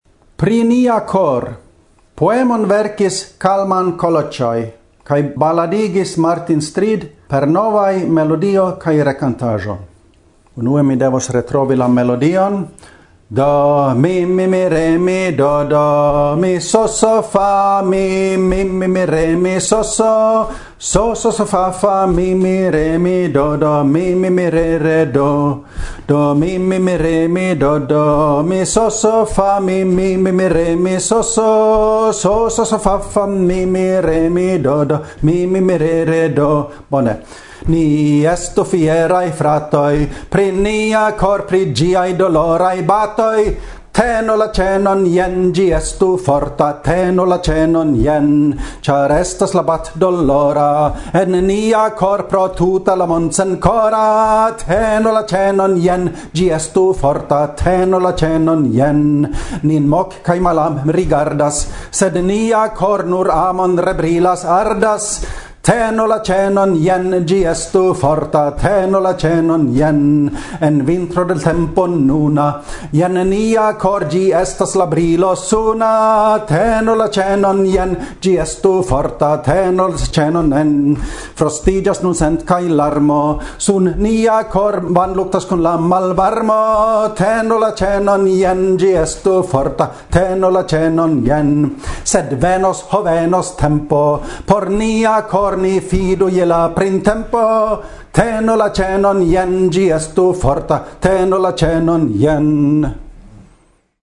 Baladoj kun tonsilaba enkonduko